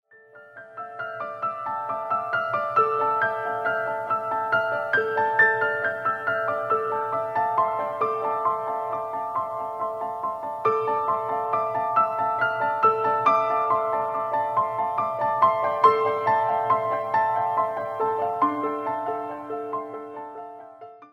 percussionist